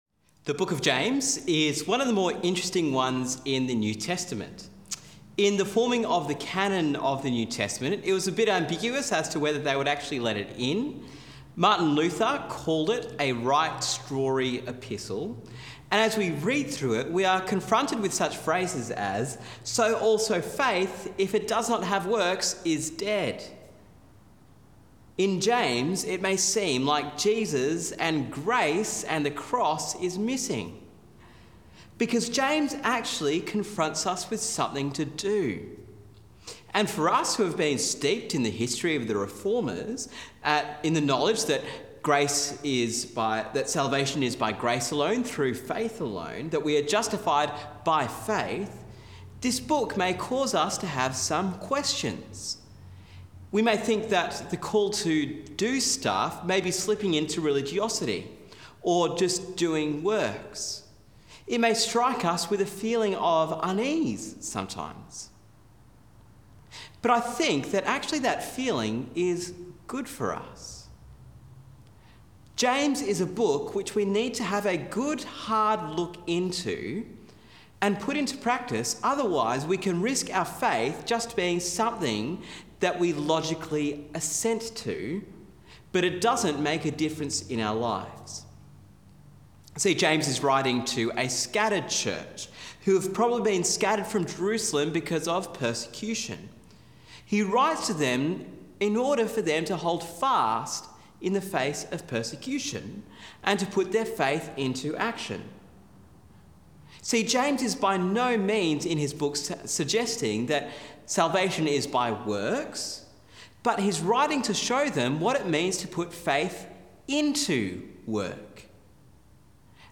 Listen to the sermon on James 1:9-18 in our Faith in Action series.